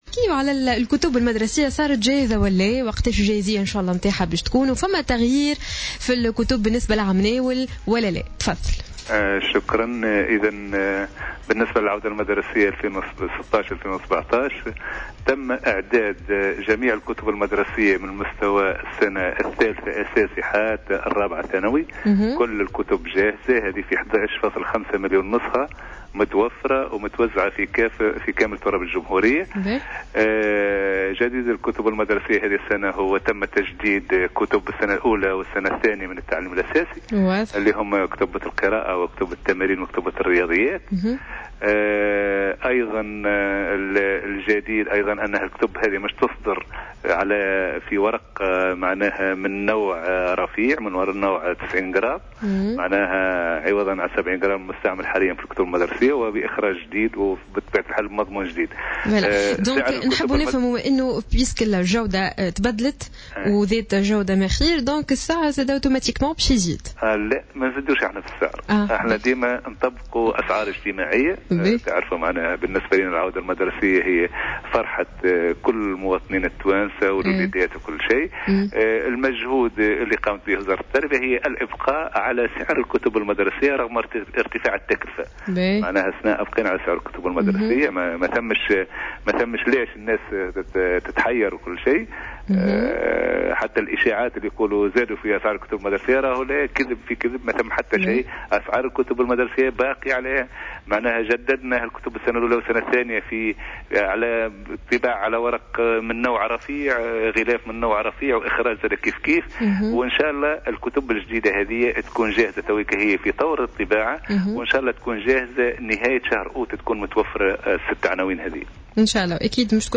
وكشف لسود في مداخلة له اليوم على "الجوهرة أف أم" عن قائمة الكتب المدرسية الجديدة والتي تهم السنة الأولى والثانية من التعليم الأساسي (كتب القراءة والتمارين والرياضيات), مشيرا إلى أنها ستصدر في ورق من نوع رفيع وبإخراج جديد مع الحفاظ على نفس الأسعار على الرغم من ارتفاع الكلفة، وفق تعبيره.